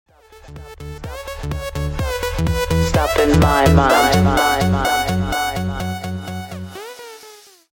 Sound Buttons: Sound Buttons View : Short Drop Alert
short-drop-alert.mp3